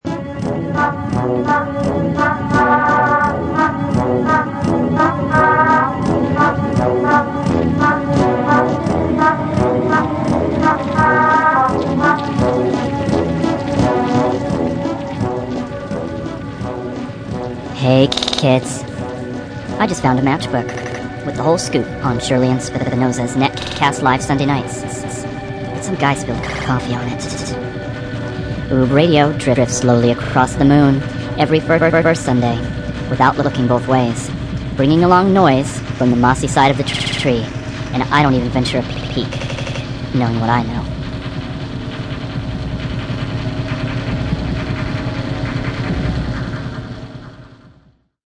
s&S’s archive of past LIVE webcasts …& more!